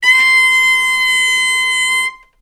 vc-C6-ff.AIF